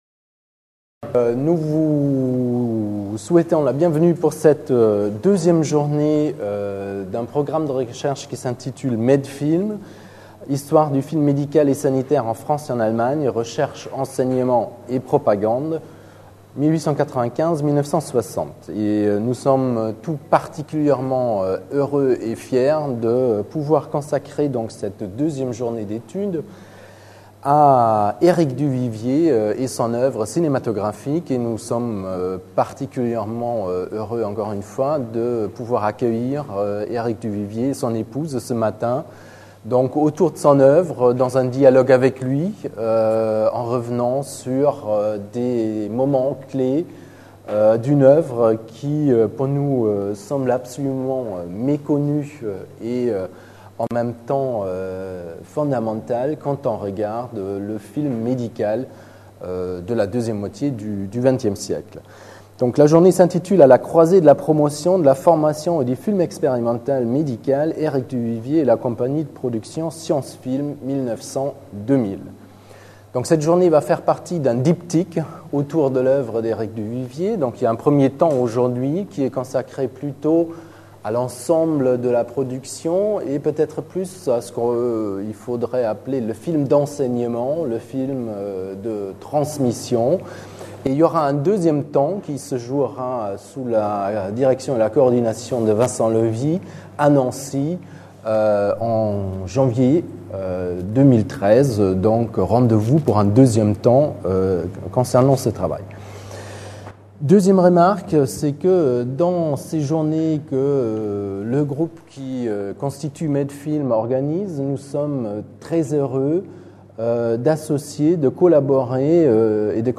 Entretien
MEDFILM Journée d’étude n°2